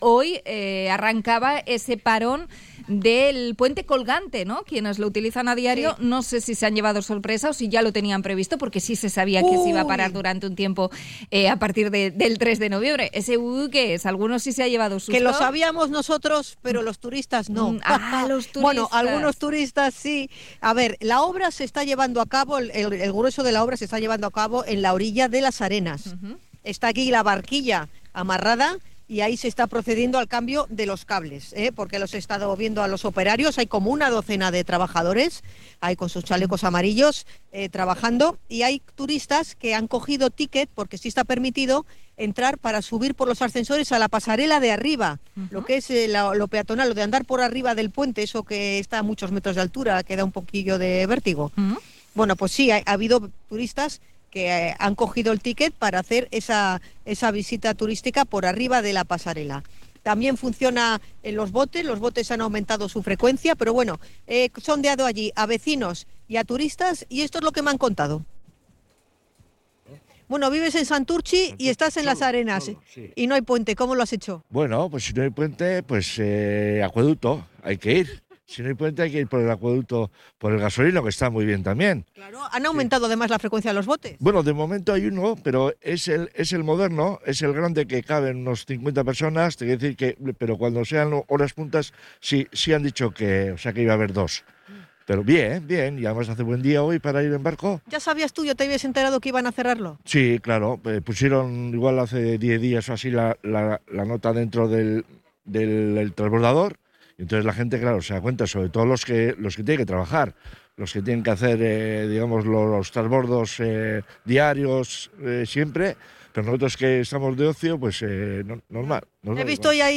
Encuesta con los usuarios del puente colgante tras su cierre por un mes desde hoy
Testimonios a pie de puente